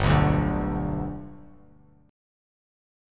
G Chord
Listen to G strummed